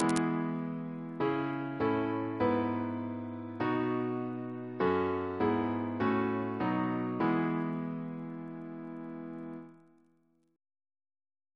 Single chant in E Composer: Ray Francis Brown (1897-1965) Reference psalters: ACP: 61; H1982: S214